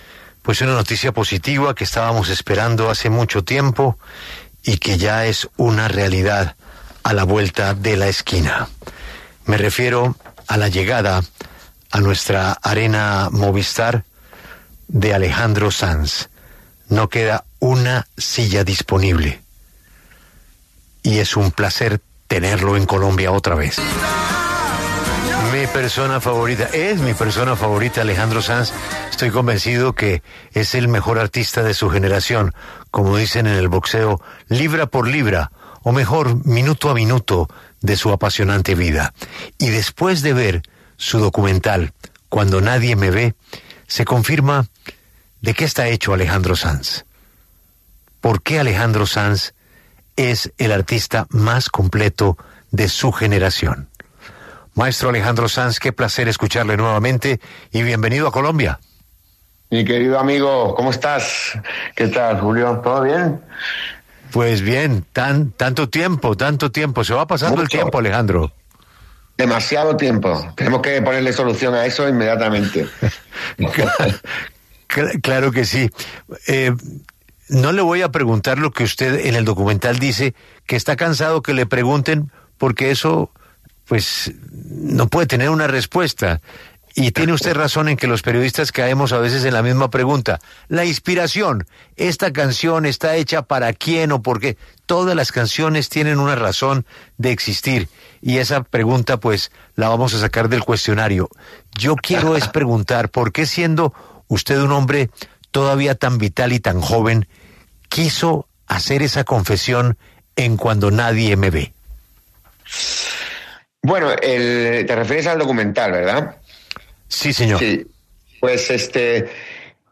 El cantautor español conversó en 6AM W sobre su nueva serie documental ‘Cuando nadie me ve’ y su regreso a Colombia este 13 y 14 de febrero el marco de su gira ‘¿Y ahora qué?’.